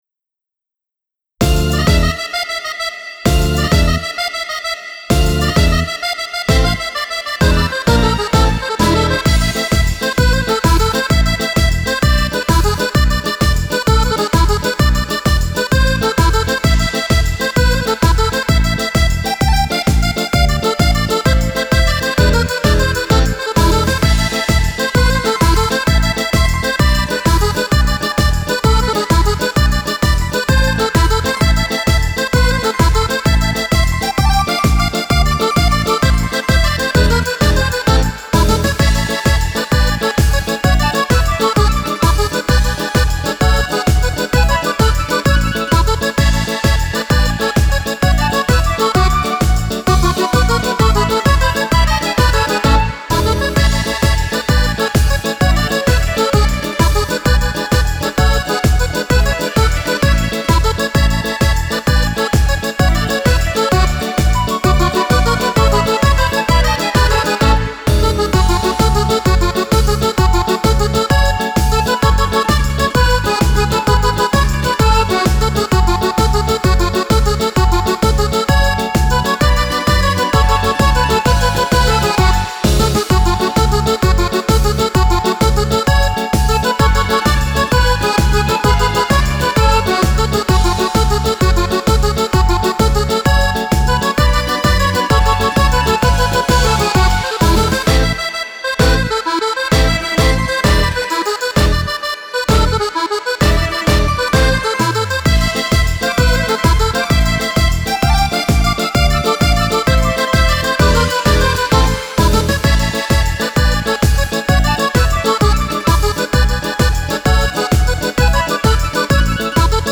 Tarantella per Fisarmonica e orchestra